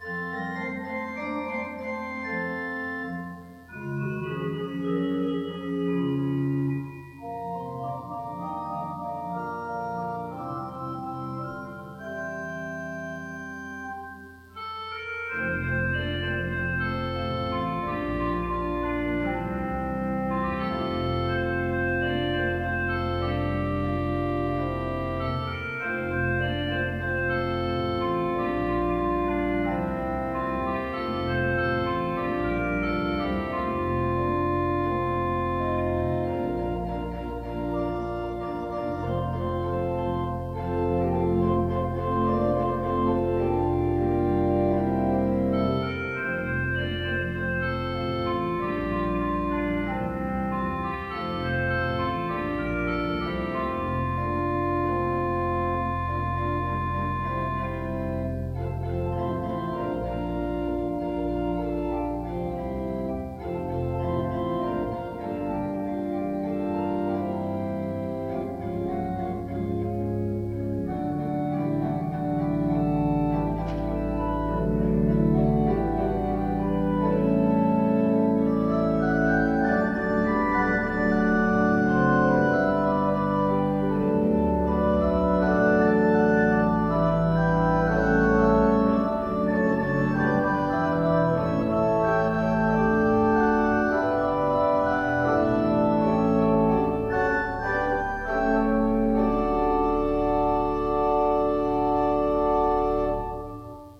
By Organist/Pianist